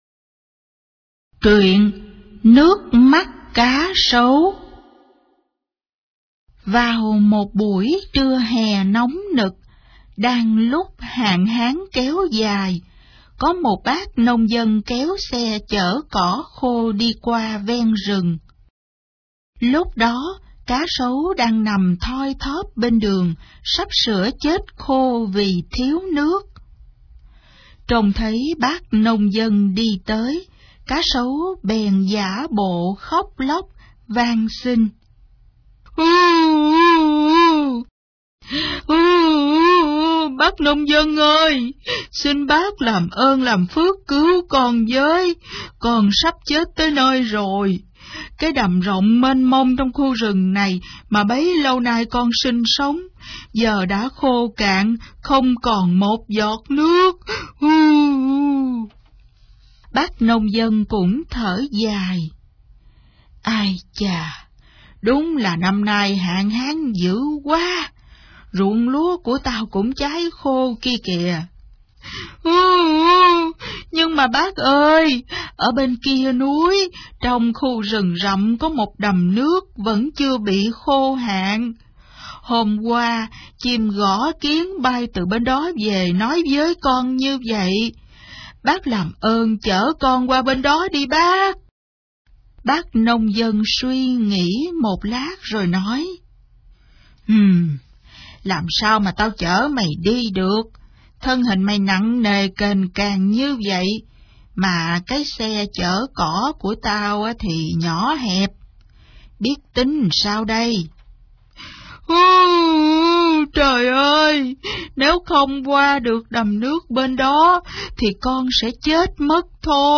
Sách nói | Nước mắt cá sấu